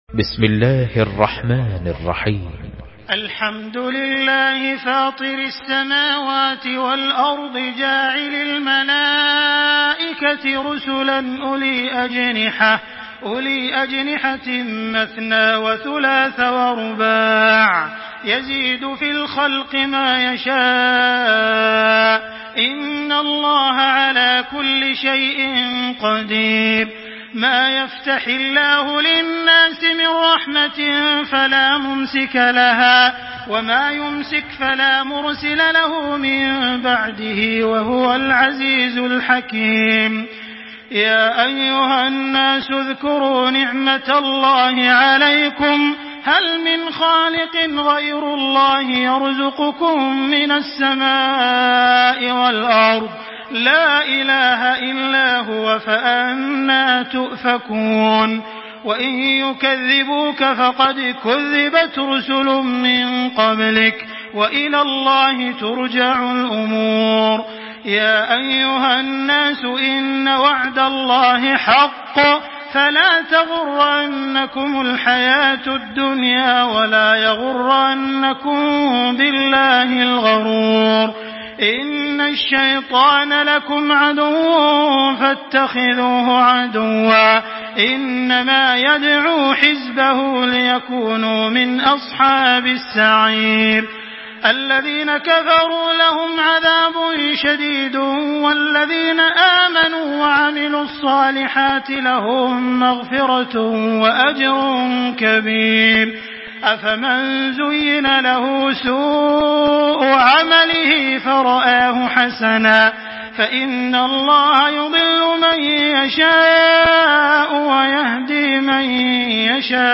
Listen and download the full recitation in MP3 format via direct and fast links in multiple qualities to your mobile phone.
Makkah Taraweeh 1425
Murattal